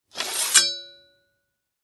Звуки фехтования
Шпагу или копье сняли со стола